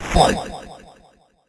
snd_boxing_fight_bc.ogg